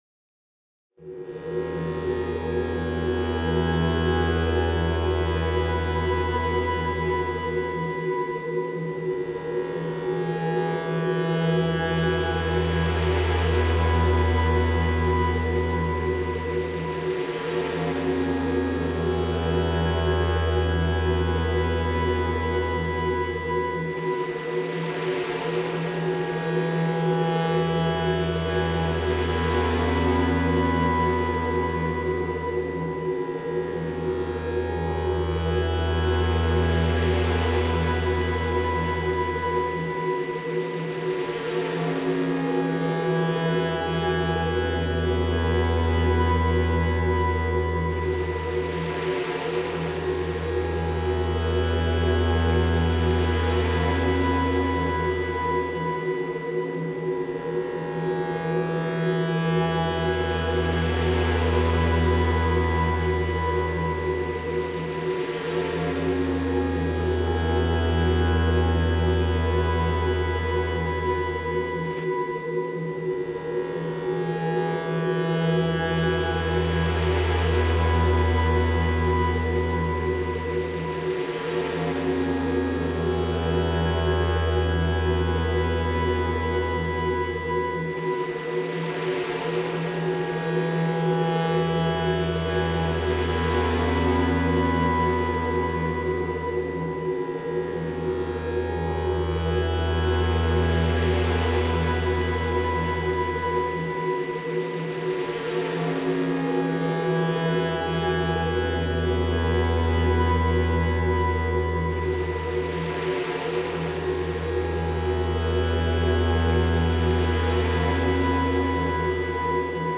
Cinematic atmospheric suspense.